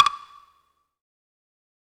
Perc (Sn).wav